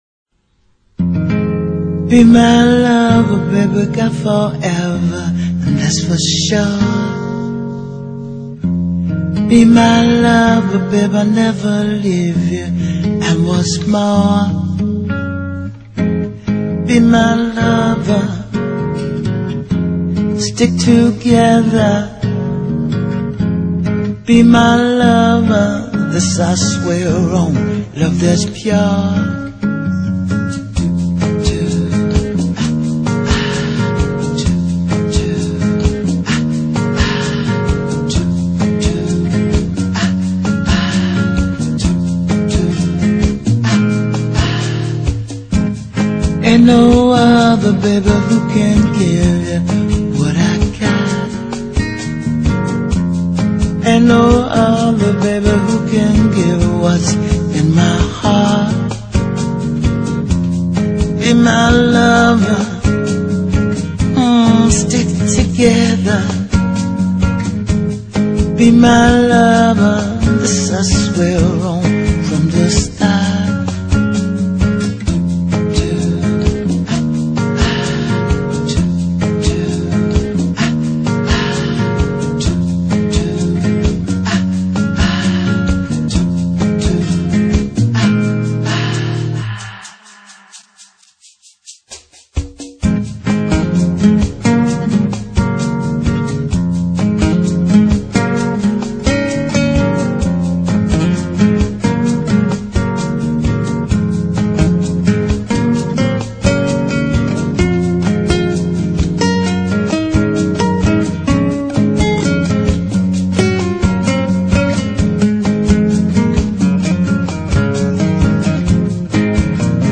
■Ladies' Jazz■